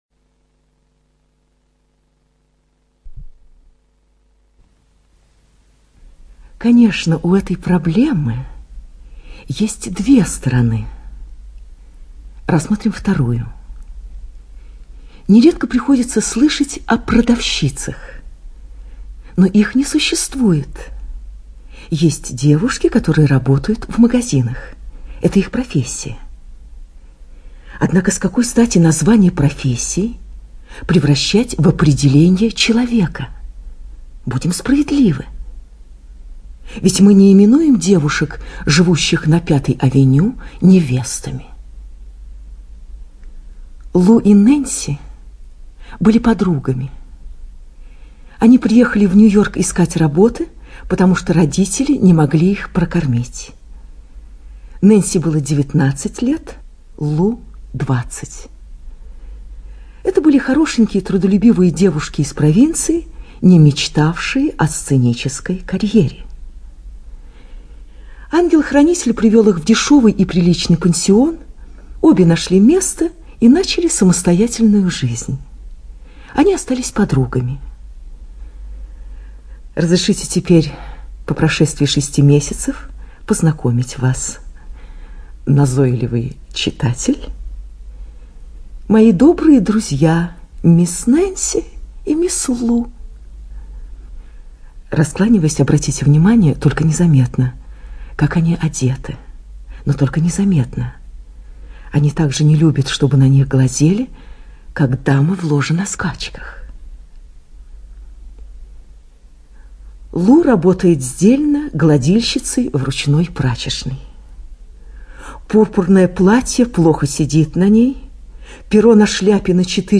ЧитаетДоронина Т.
О'Генри - Горящий светильник. 1983 (Доронина Т.)(preview).mp3